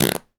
pgs/Assets/Audio/Comedy_Cartoon/fart_squirt_11.wav at master
fart_squirt_11.wav